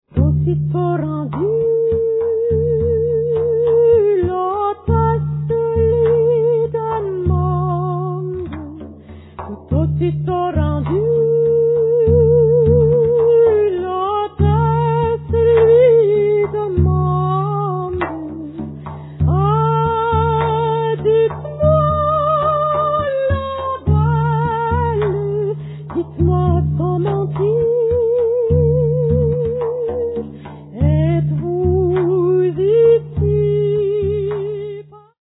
French and Breton folk songs